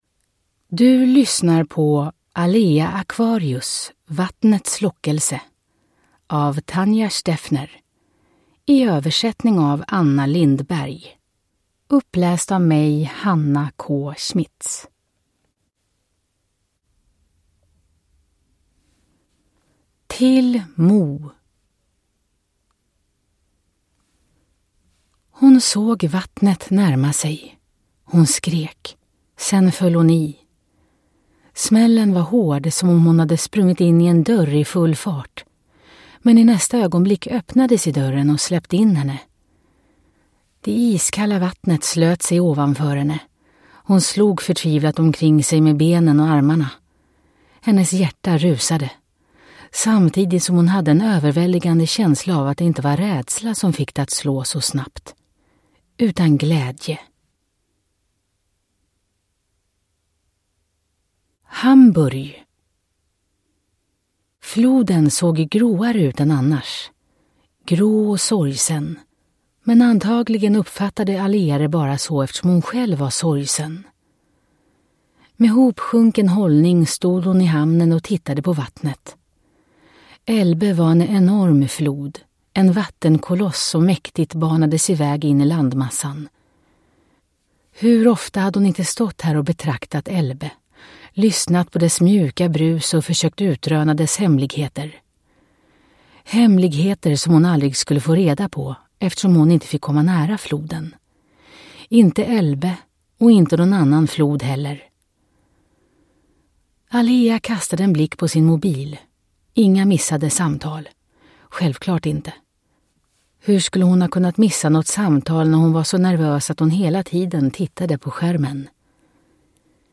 Alea Aquarius: Vattnets lockelse (1) (ljudbok) av Tanya Stewner